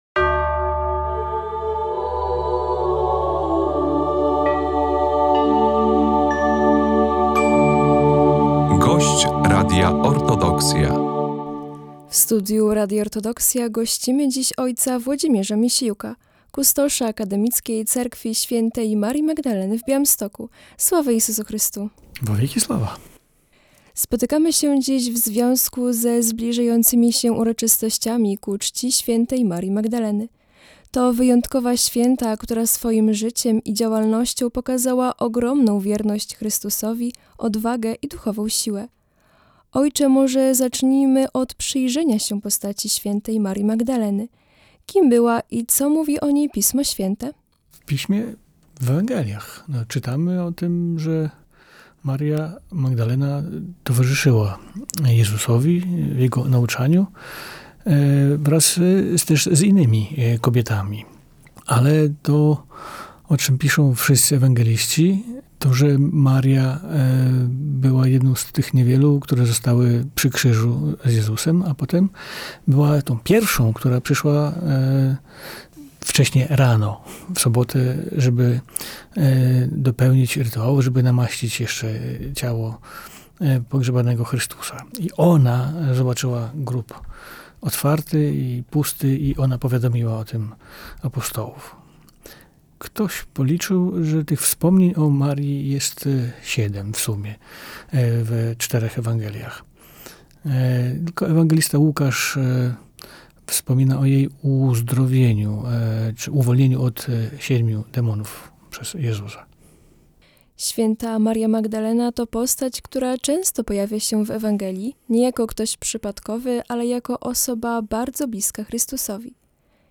rozmowy